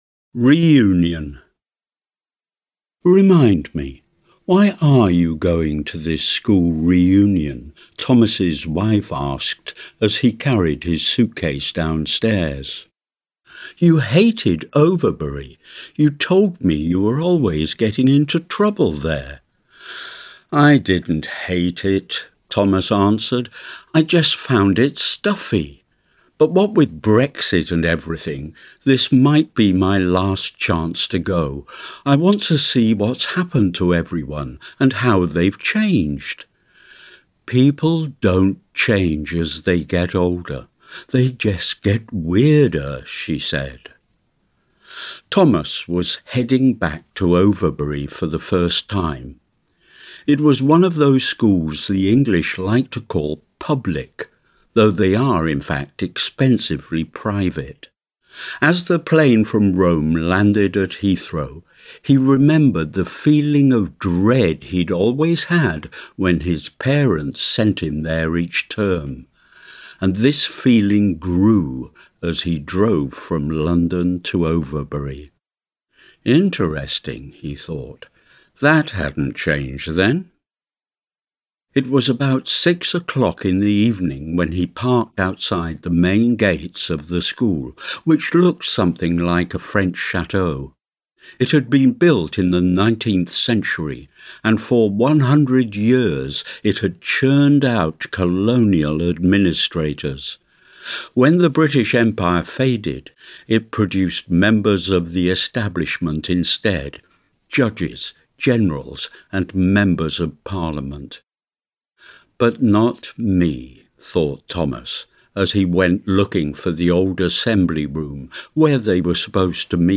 Short Story